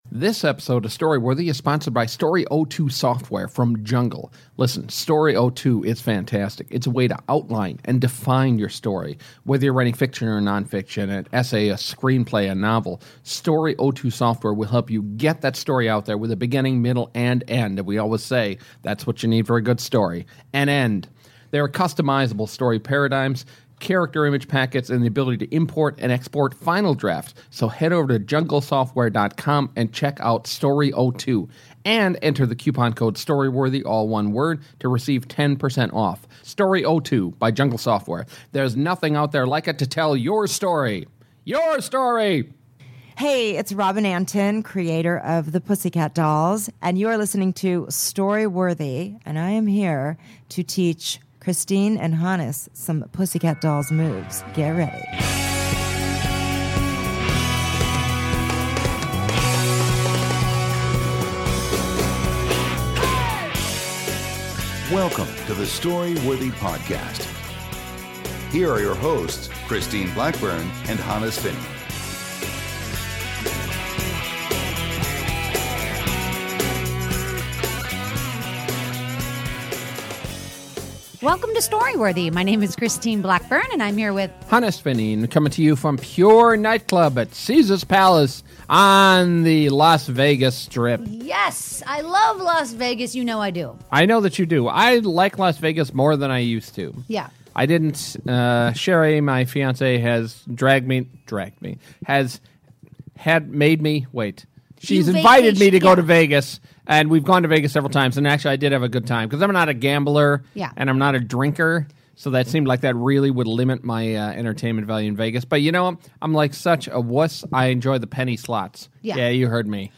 Robin Antin, choreographer and founder of the burlesque troupe The Pussycat Dolls, talks about dance and how she created one of the best-selling girl groups of all time.